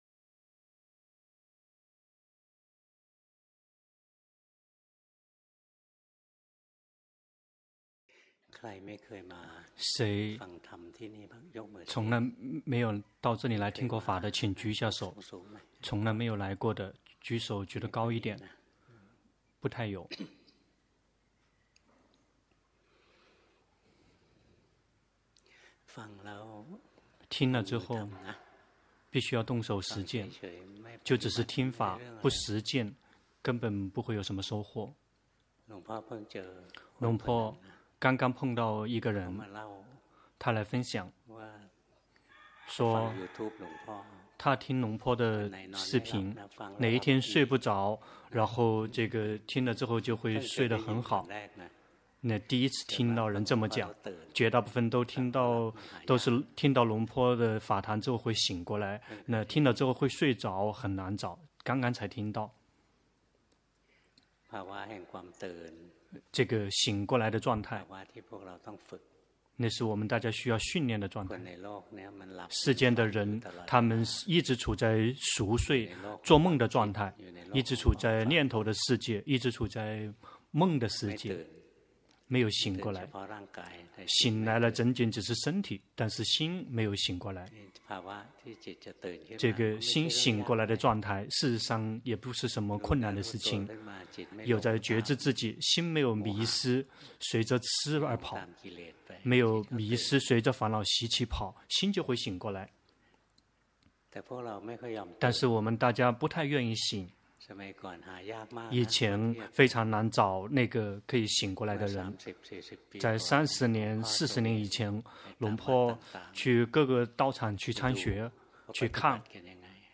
泰國解脫園寺 同聲翻譯